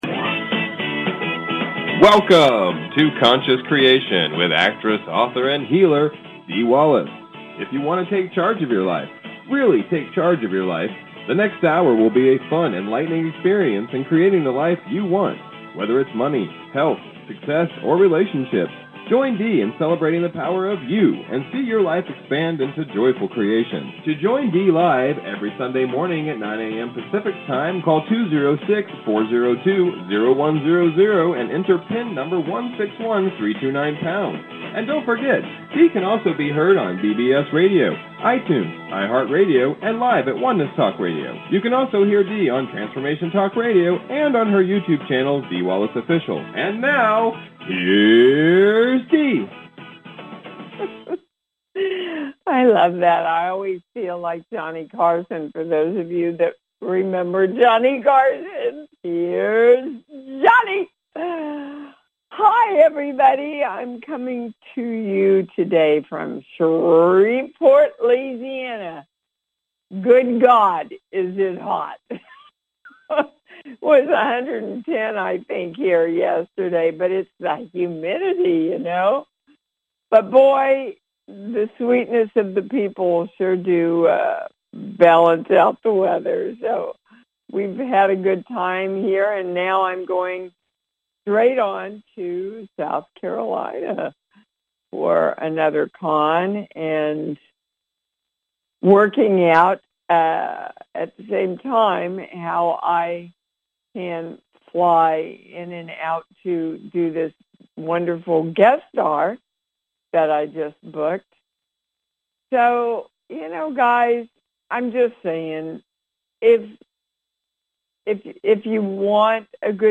Talk Show Episode, Audio Podcast, Conscious Creation and with Dee Wallace on , show guests , about Conscious Creation with Dee Wallace, categorized as Health & Lifestyle,Paranormal,Philosophy,Emotional Health and Freedom,Personal Development,Self Help,Society and Culture,Spiritual,Psychic & Intuitive